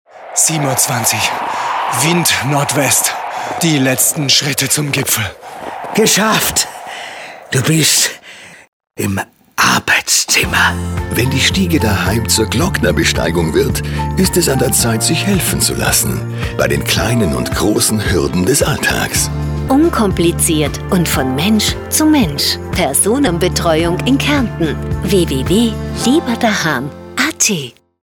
Unsere Radiospots